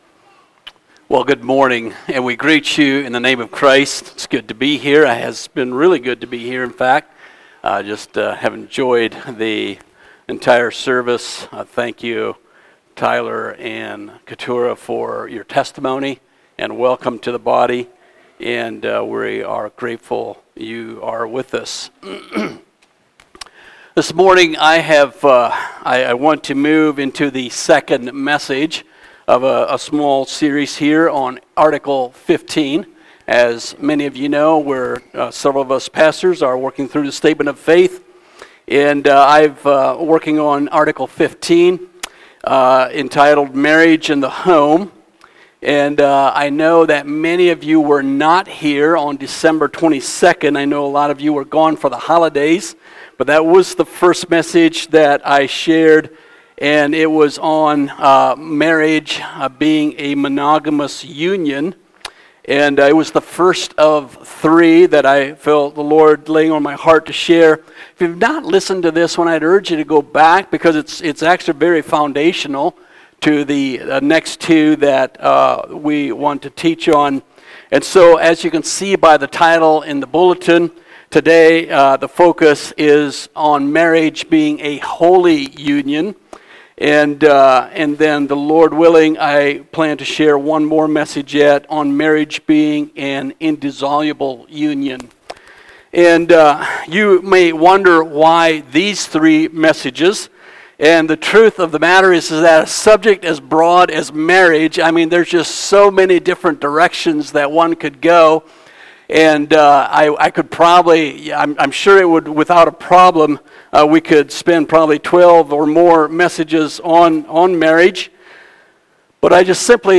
Sermons Statement of Faith: Article 15